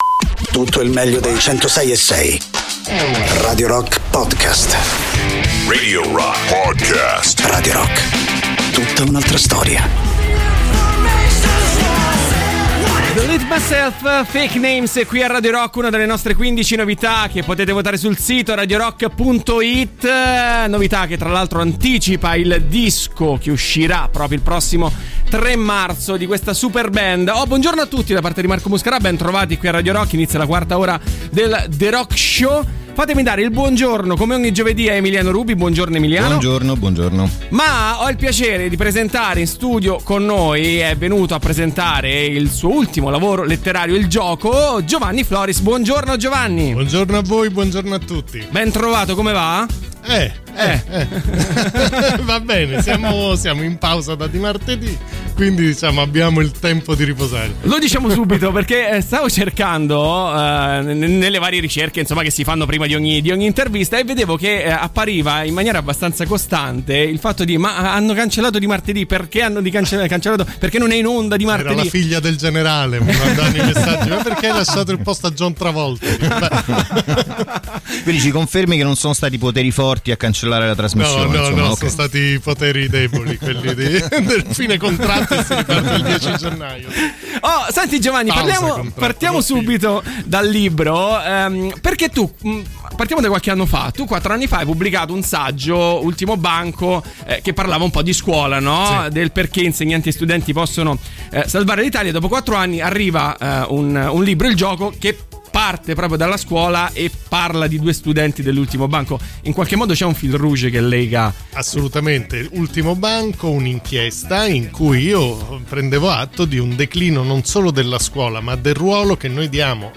Interviste: Giovanni Floris (15-12-22)